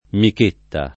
[ mik % tta ]